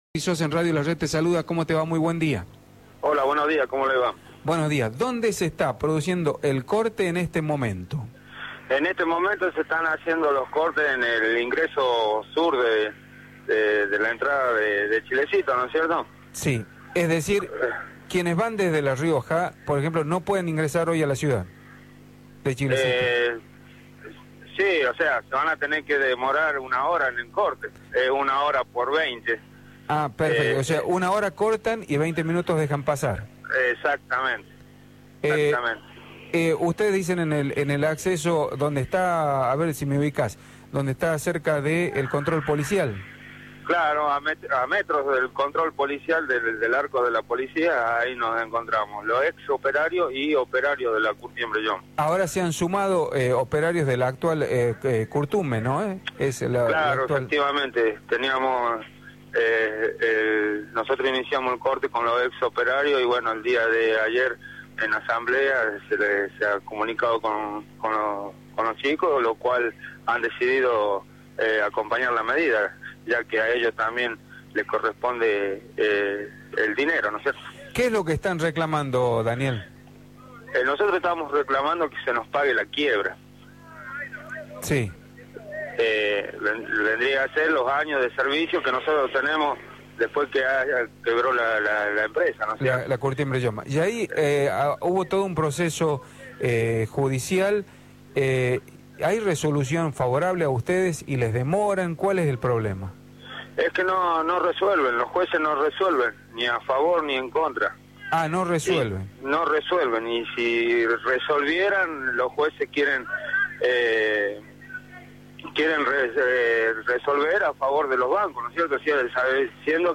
por Radio La Red